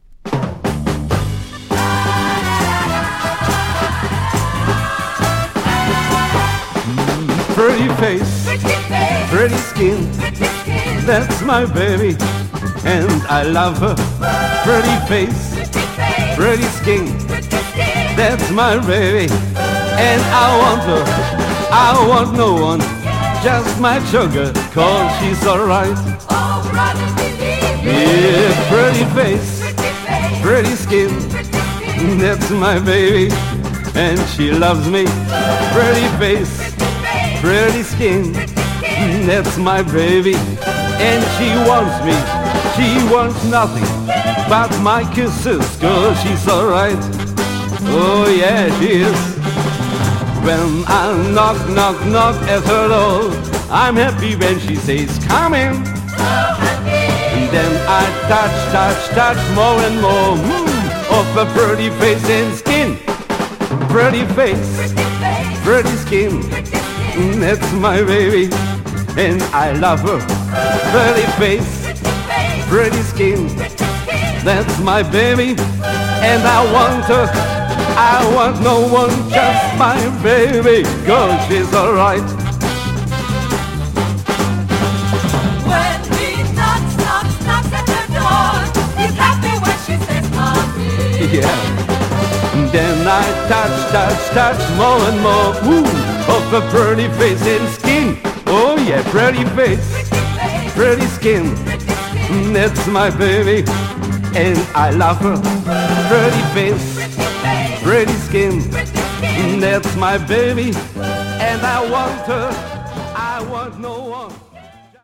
format: 7inch